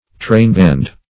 Trainband \Train"band`\, n.; pl. Trainbands.